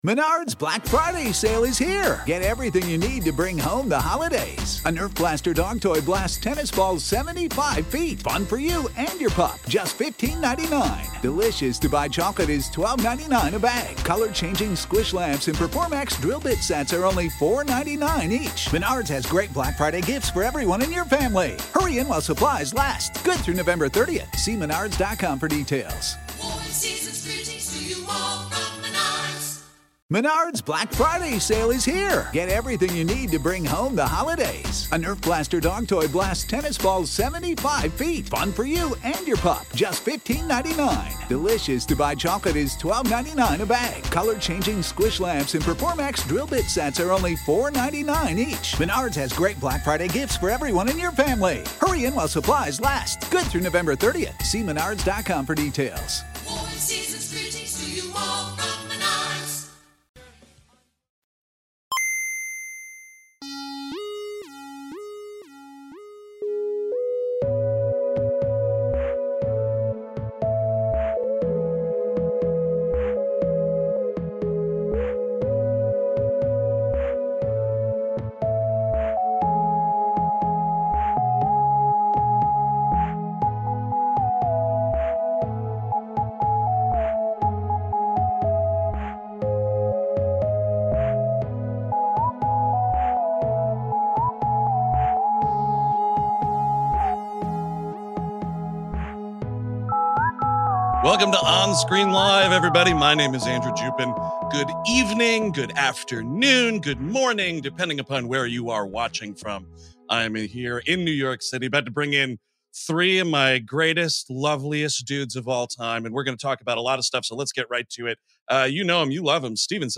On their On-Screen LIVE! show, the guys dig into box office figures, react to the latest trailers, and chat about new film & tv they're watching!
On this edition of On-Screen Live! , the guys are reacting to last Tuesday's Oscar nominations, doing a quick check-in on what just played at Sundance, chatting about Brandon Cronenberg's Infinity Pool , reacting to the Succession season 4 trailer, and taking a look at the box office success of Pathaan ! This is of course the audio-only edition of On-Screen Live! , if you want the full experience, check out the show on our YouTube channel.